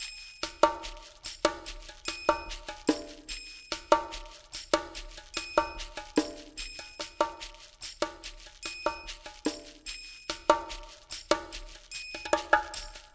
BOL PERCMX-L.wav